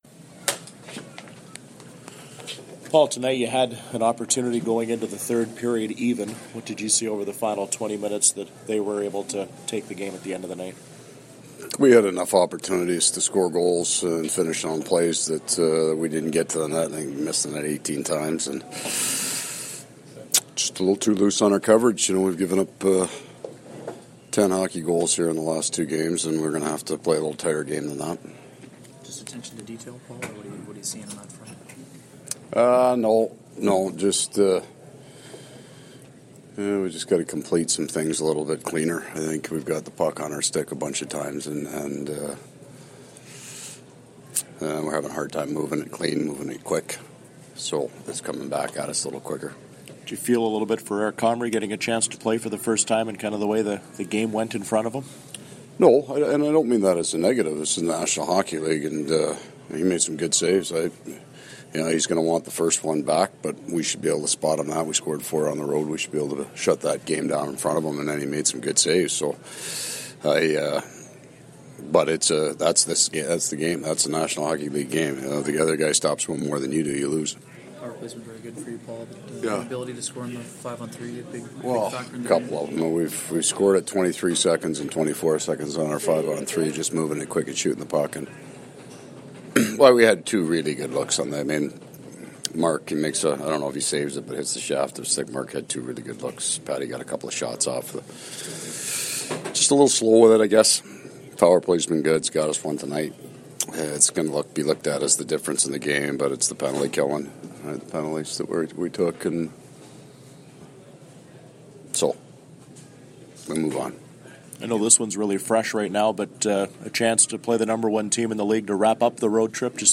December-7-2017-Coach-Maurice-post-game.mp3